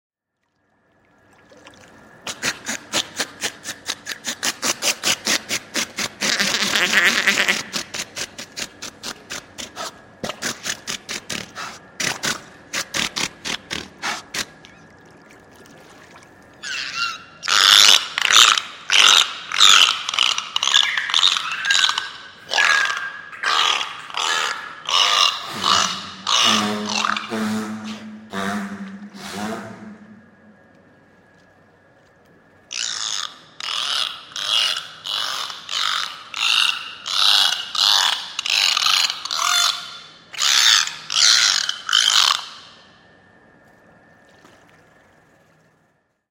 Белуха хрюкает на поверхности воды